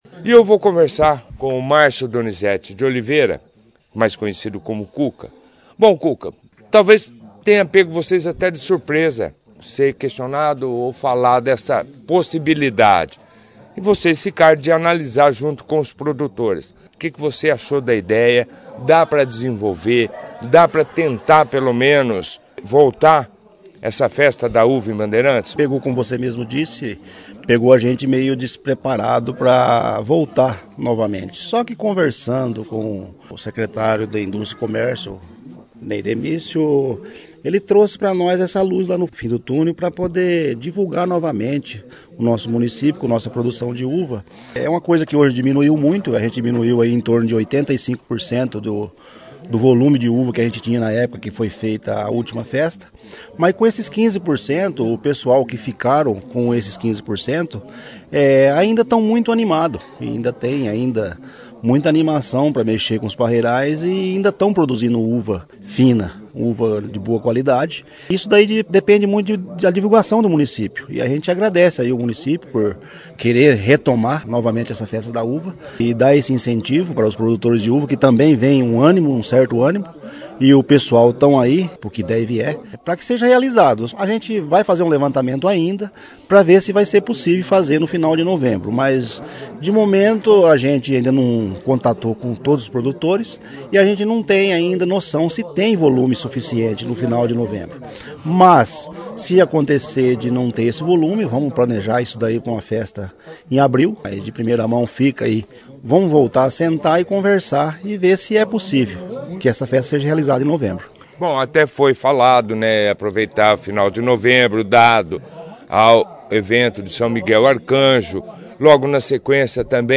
A reunião foi destaque da 1ª edição do jornal Operação Cidade desta segunda-feira